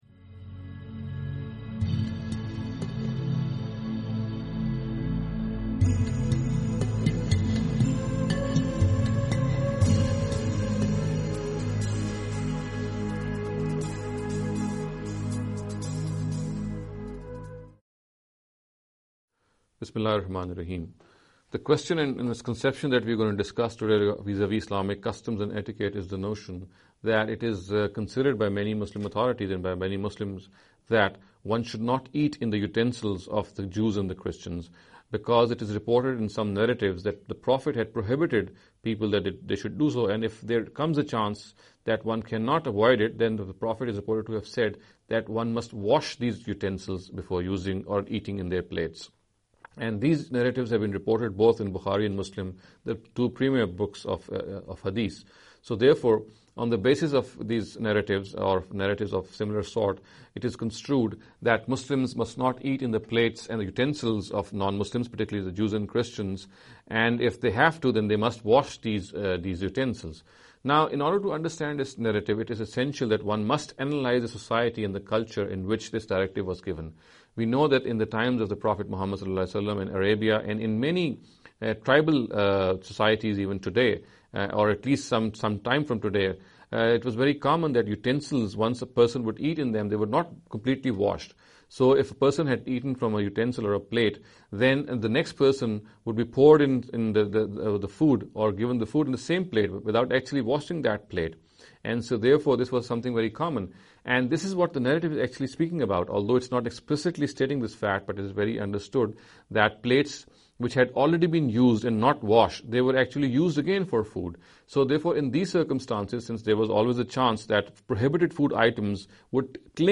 This lecture series will deal with some misconception regarding the Islamic Customs & Etiquette.